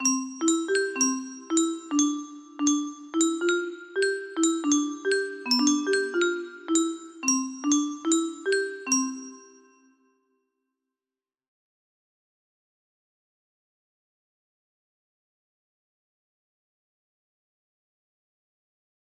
Unknown Artist - Untitled music box melody
Grand Illusions 30 music boxes More